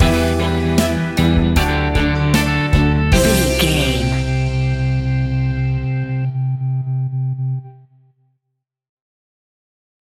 Pop Rock Anthem Short Stinger.
Aeolian/Minor
bold
happy
upbeat
bouncy
drums
bass guitar
electric guitar
keyboards
hammond organ
acoustic guitar
percussion